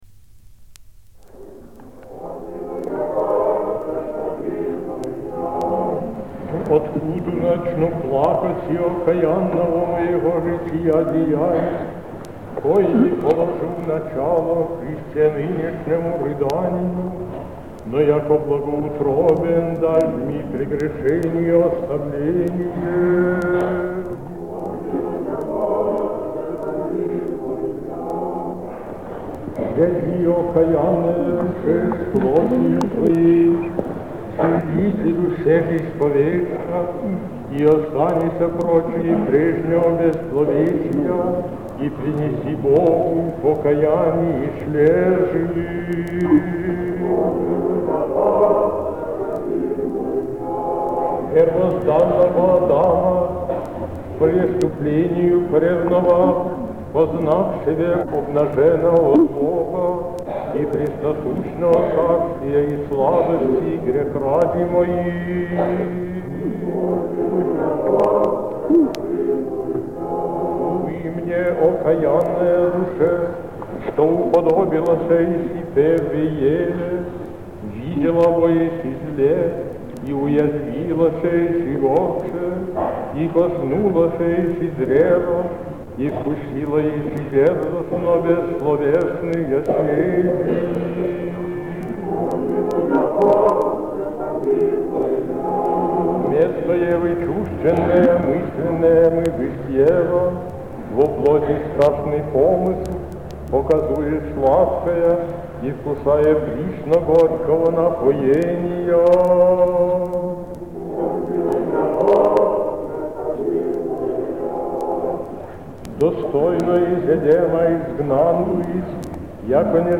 Запись с пластинки "Патриарх Алексий (Симанский) и его время"